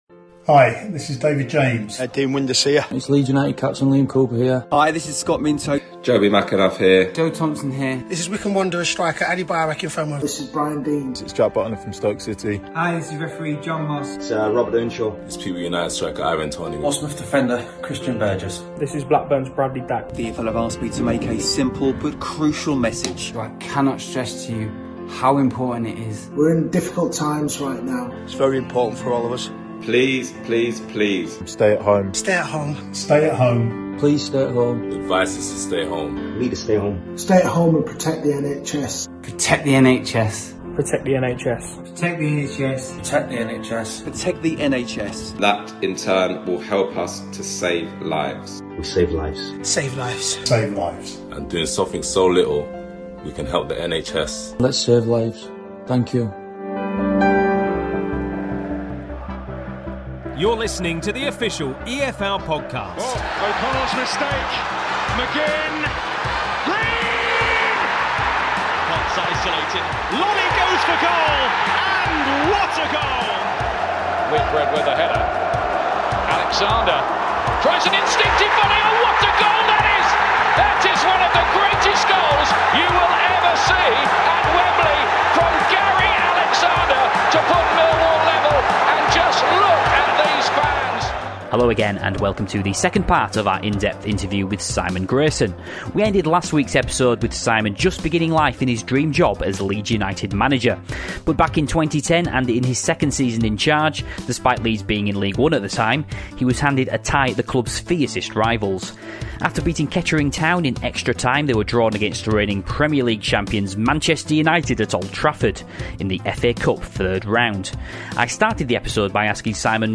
In the second part of his extended interview, Simon Grayson opens up on the stories behind that FA Cup win with League One Leeds United at Old Trafford, three more promotions and how he was portrayed in the Sunderland 'Til I die documentary.